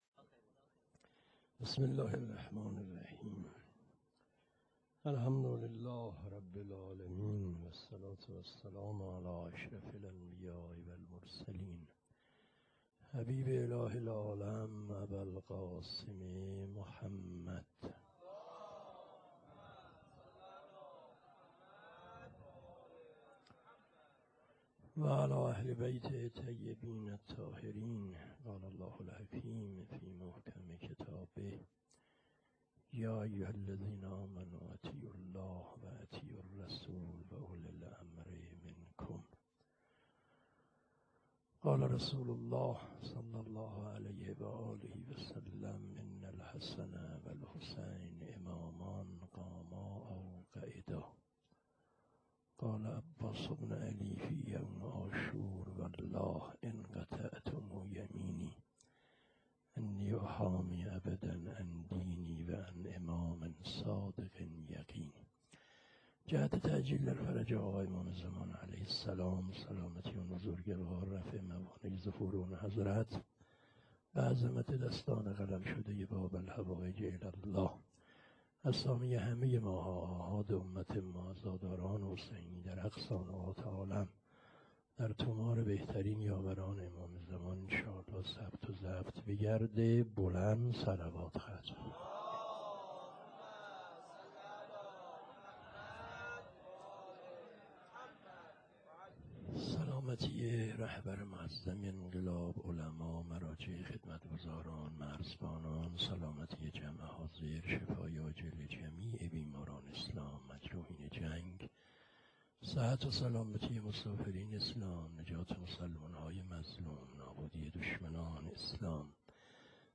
شب تاسوعا _ سخنرانی حاج آقا فلسفی.wma